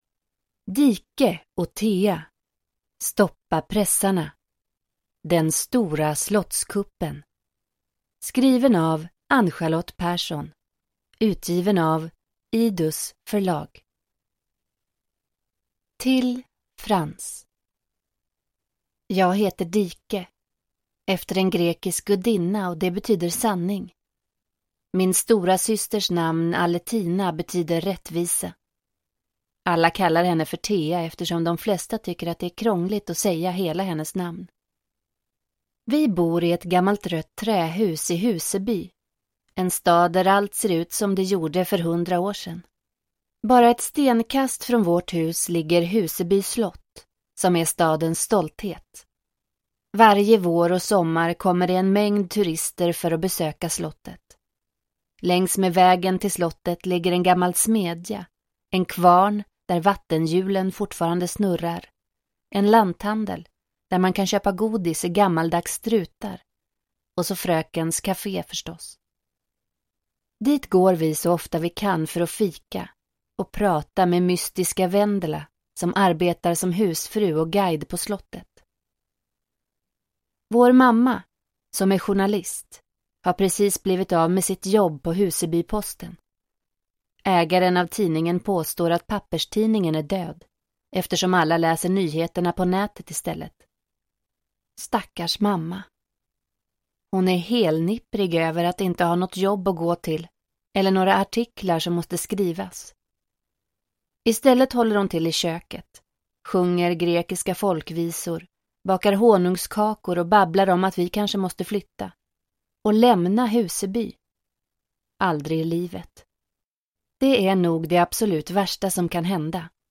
Stoppa pressarna! : Den stora slottskuppen – Ljudbok – Laddas ner